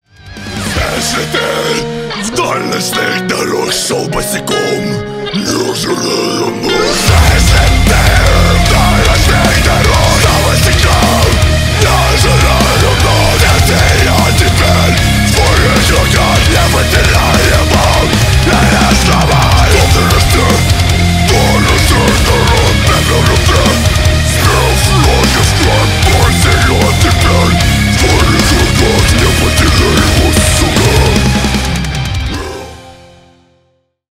Рок Металл # громкие # кавер